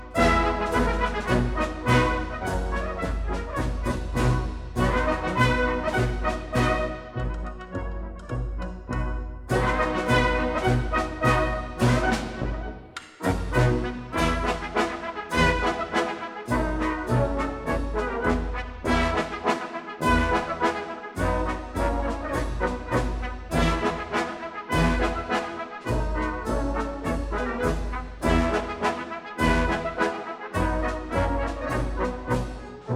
1962 stereo recording